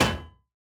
Minecraft Version Minecraft Version latest Latest Release | Latest Snapshot latest / assets / minecraft / sounds / block / heavy_core / break2.ogg Compare With Compare With Latest Release | Latest Snapshot
break2.ogg